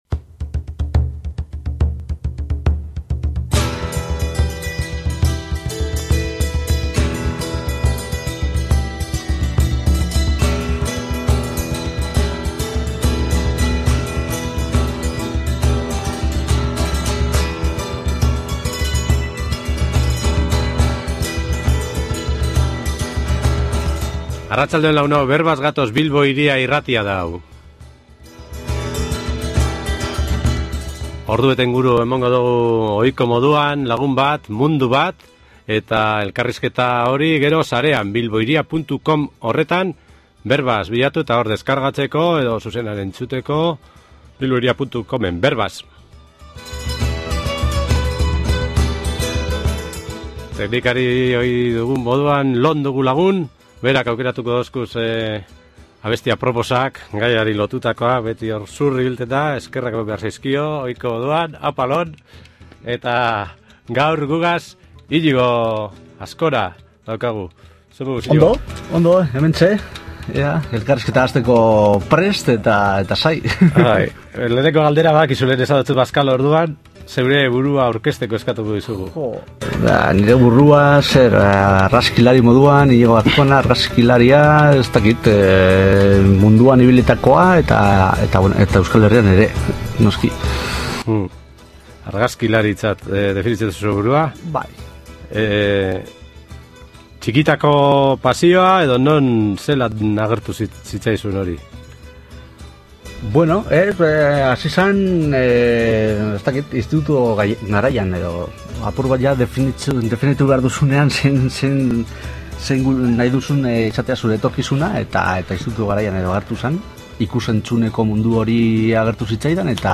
Solasaldi gozoan ordubete inguru.